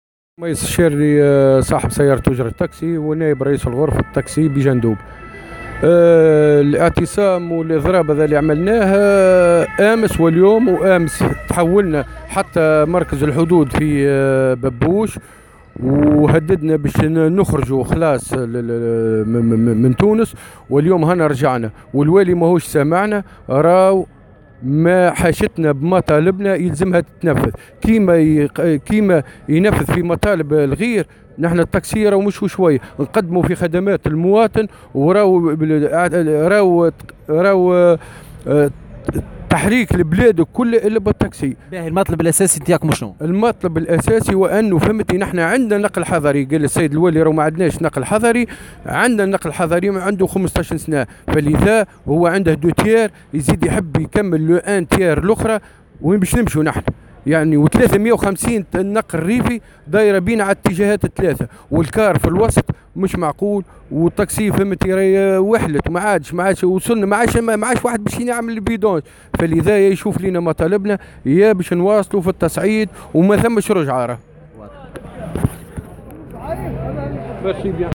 وأوضح في تصريح لـ "الجوهرة أف أم" اليوم الأربعاء أنهم في إضراب منذ يوم أمس احتجاجا على خطة الولاية للزيادة في الخط الحضري لفائدة أصحاب سيارات النقل الريفي والحافلات وما سيؤثر سلبا على نشاطهم بحسب ترجيحه، مؤكدا انهم سيواصلون في إضرابهم واعتصامهم وتهديدهم بالخروج بالقوة من الأراضي التونسية.